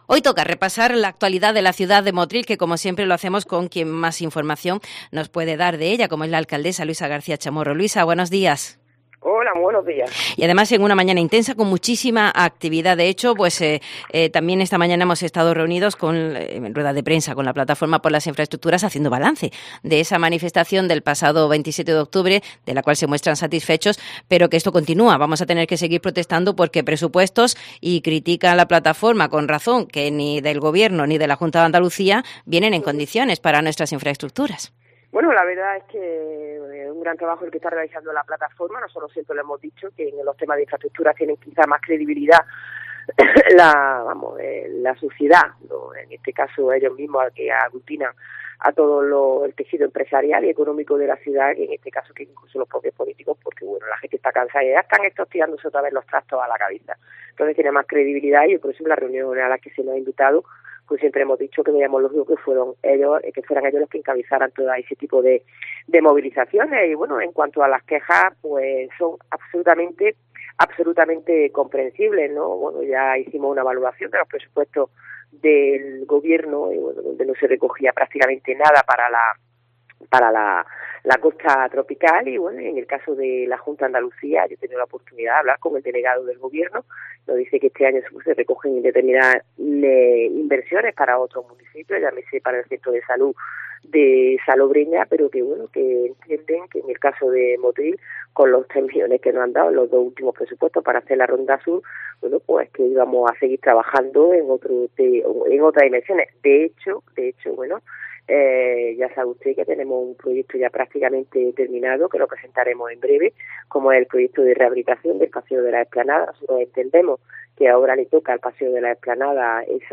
Repasamos una semana más la actualidad de Motril con la alcaldesa Luisa García Chamorro.